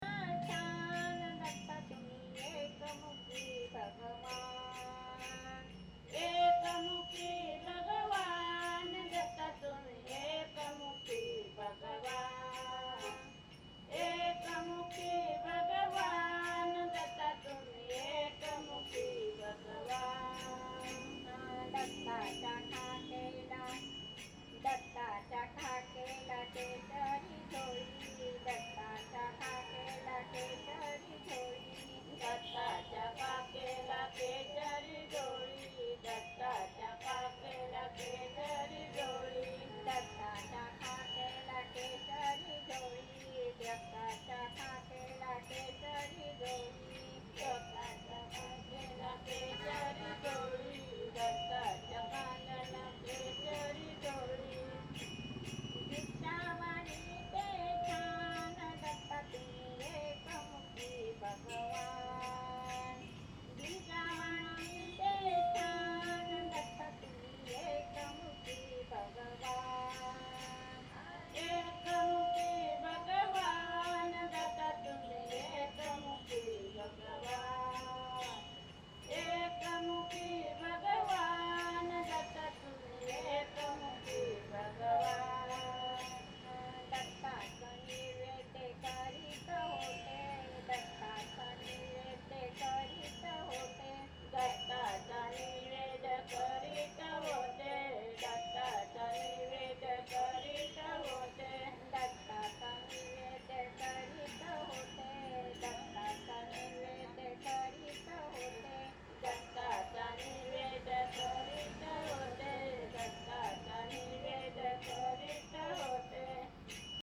Au milieu de la rue, ces femmes ont tracé un mandala de pigments sur le trottoir, installé une tenture, branché quelques micros sur un ampli, et se sont mises à chanter.